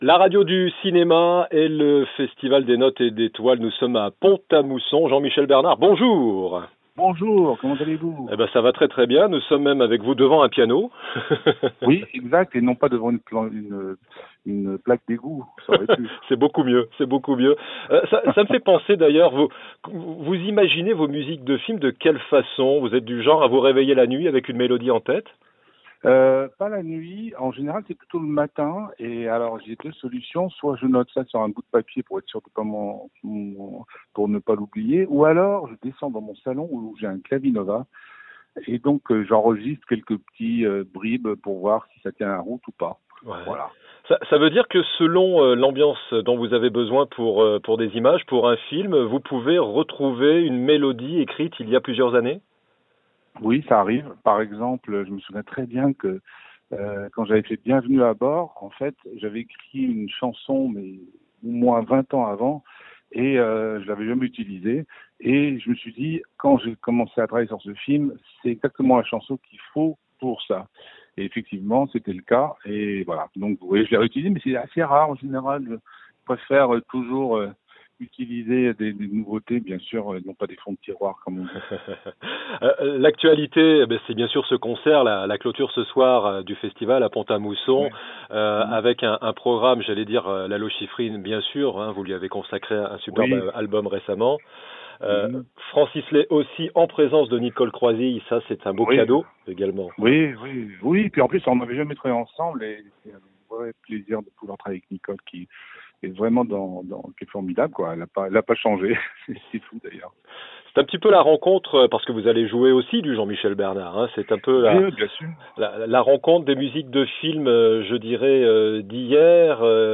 2. Podcasts cinéma : interviews | La Radio du Cinéma